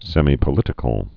(sĕmē-pə-lĭtĭ-kəl, sĕmī-)